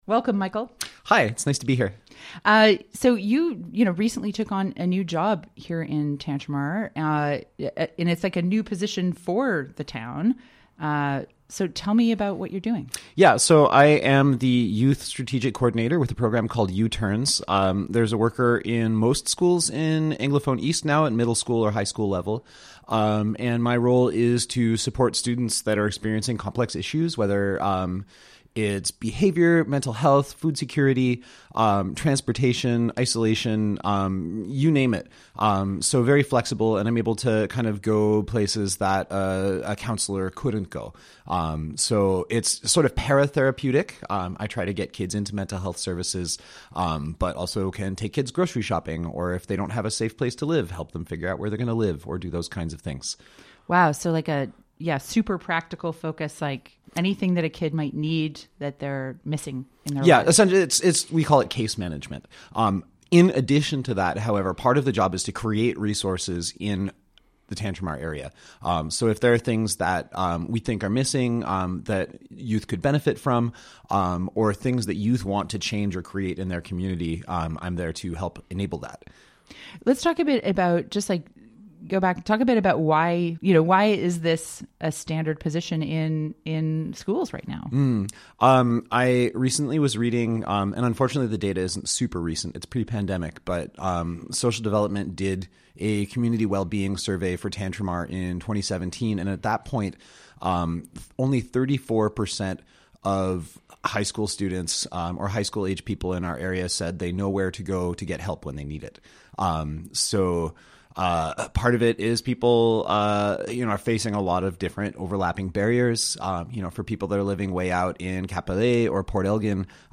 CHMA spoke to him about his new role and the upcoming show.